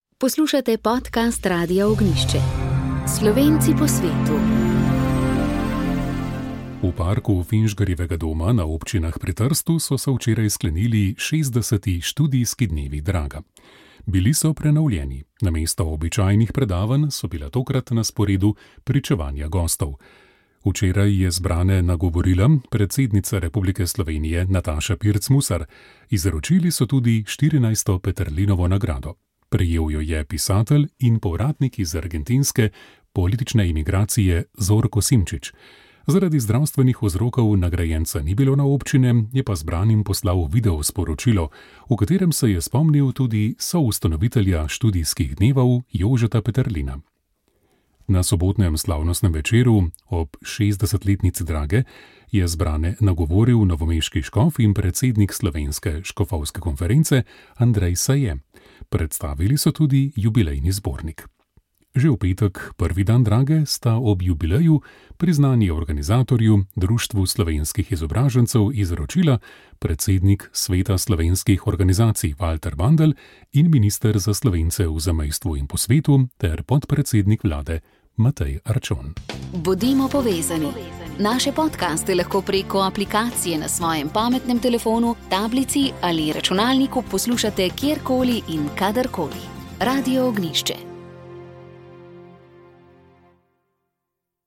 Molili so radijski sodelavci.